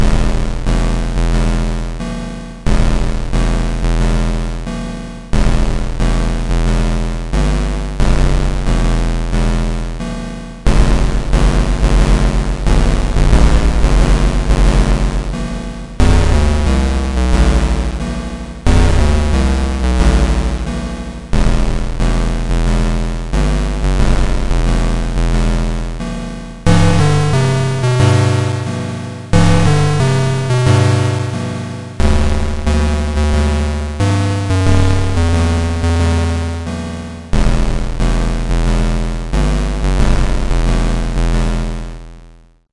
Bodhran 传统爱尔兰音乐 " Bodhran爱尔兰鼓 葬礼节拍
标签： 慢鼓声 慢鼓声 游行 葬礼 葬礼进行曲
声道立体声